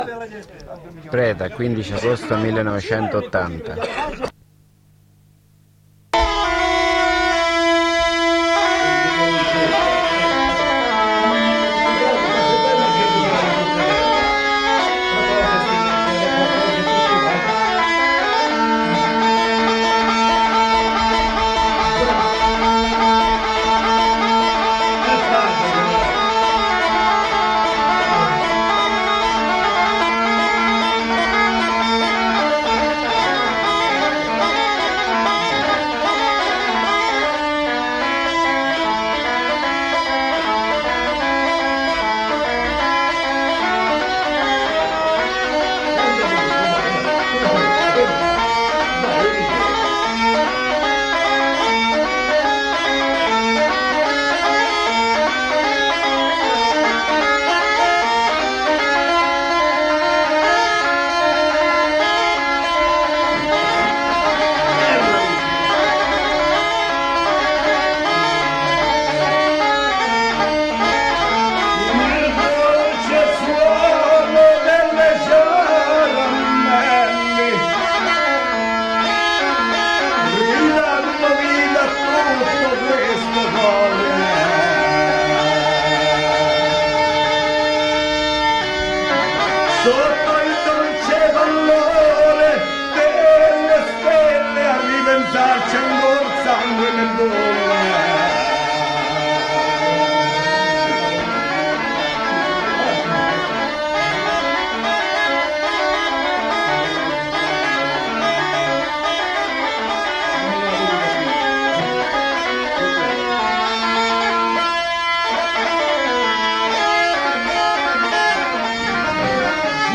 ciaramelle
Supporto: Nastro bobina 13 cm
Ottava rima